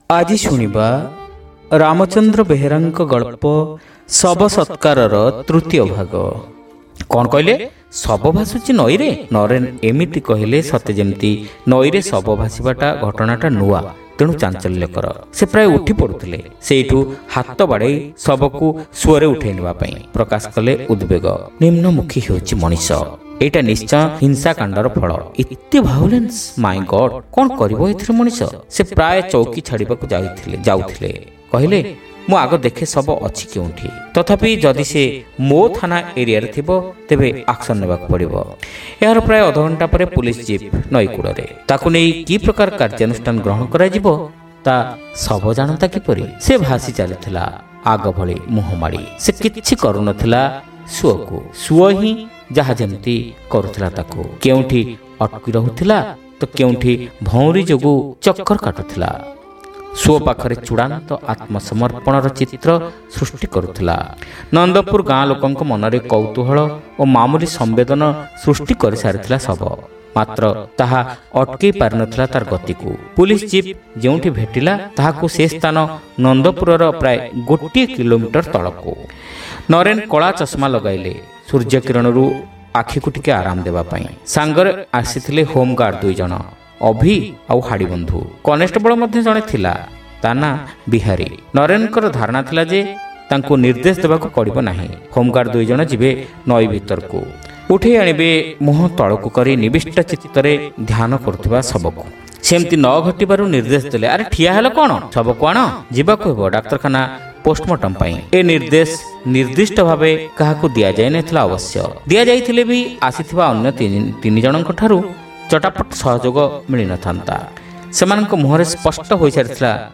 ଶ୍ରାବ୍ୟ ଗଳ୍ପ : ଶବ ସତ୍କାର (ତୃତୀୟ ଭାଗ)